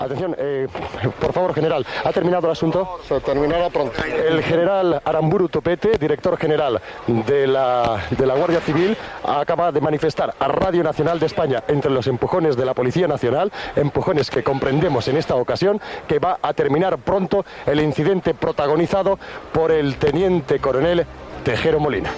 Declaracions del director general de la Guardia Civil general Aramburu Topete poc abans d'acabar l'intent de cop d'estat.
Informatiu